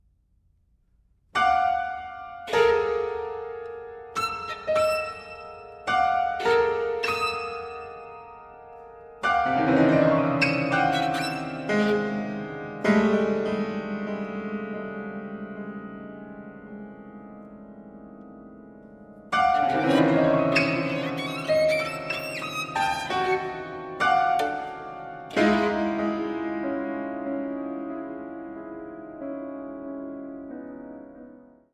violin and piano
It can at times be almost unbearably intense.